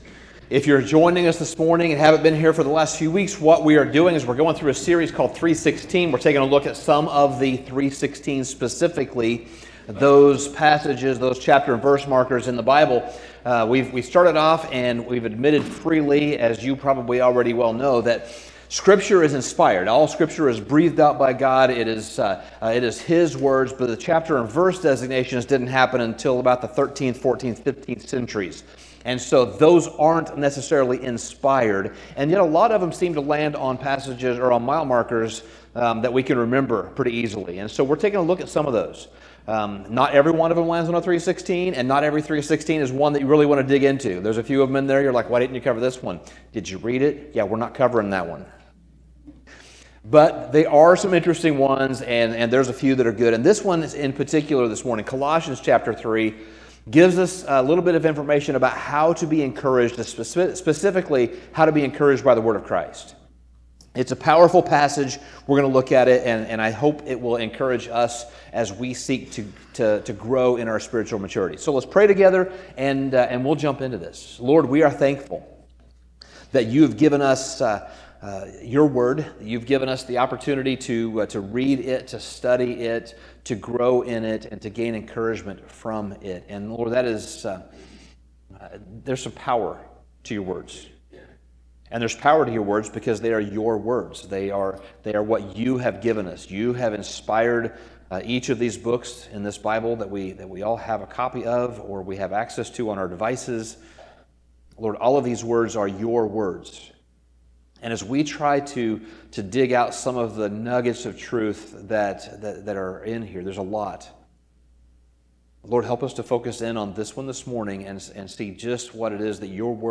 Sermon Summary In Colossians 3, Paul describes how we are to put on the new self, put on the things of Christ, and how we are to put off those things that are not of Christ.